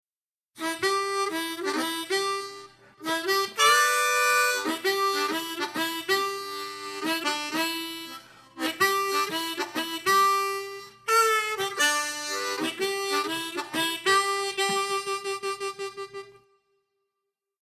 La base musical que encontrarás en la clase es en tonos MI menore y tocaremos una armónica en C tocando en quinta posición.
TÉCNICA 1: REPETICIÓN SOBRE LAS MISMAS NOTAS. Con esta técnica se presenta una idea musical y se repite idéntica o con una pequeñísima variación rítmica o melódica.